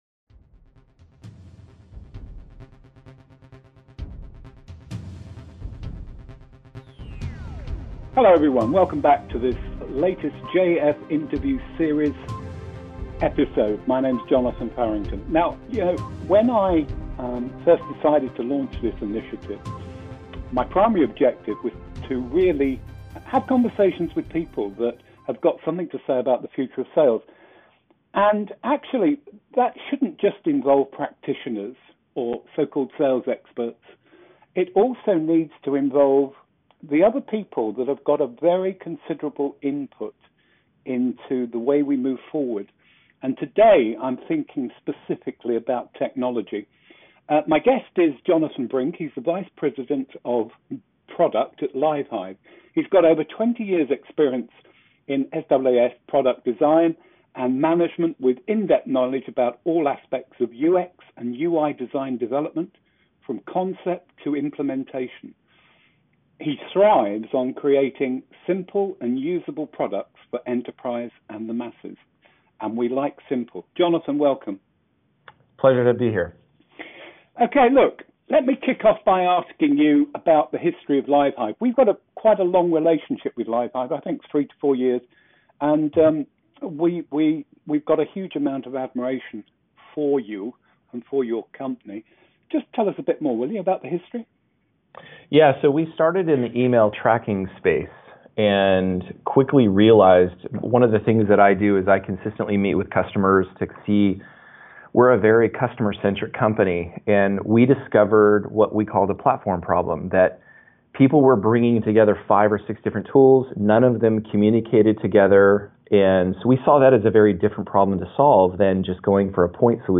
Category: Interview, Sales Enablement, Sales Tools